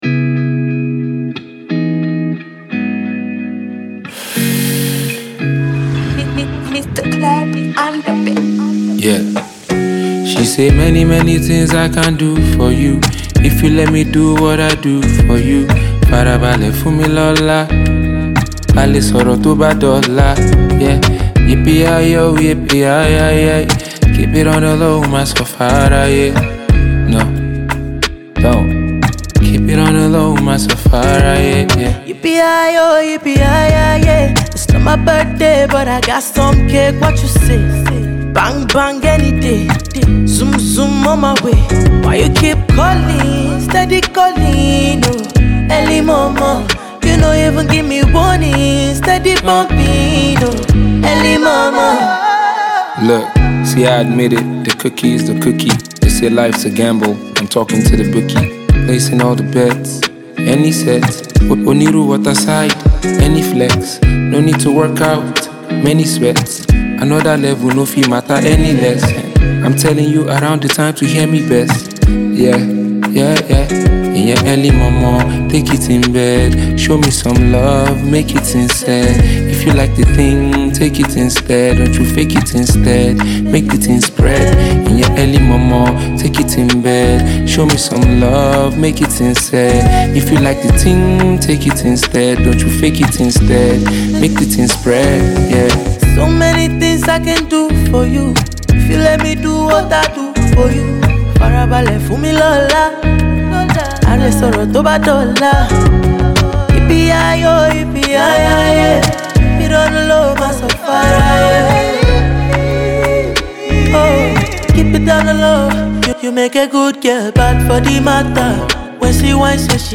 melodious record